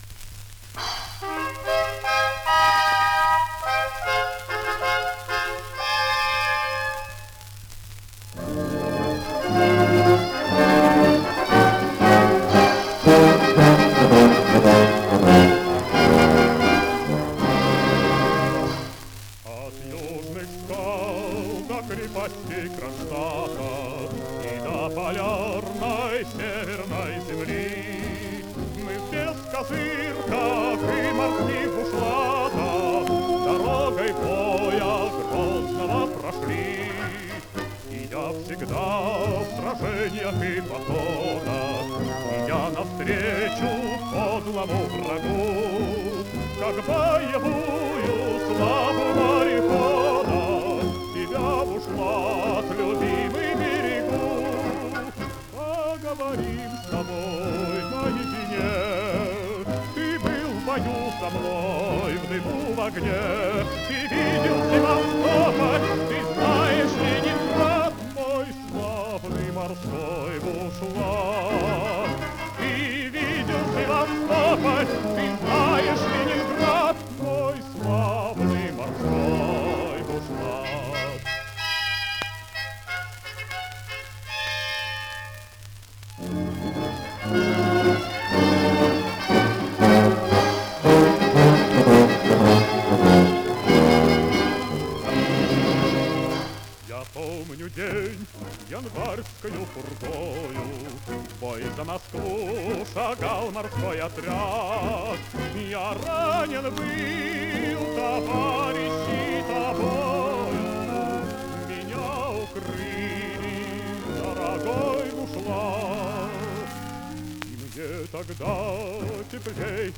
Исполнение военного времени.